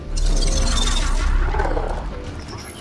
borderlands-deathtrap-spawning-noise_26207.mp3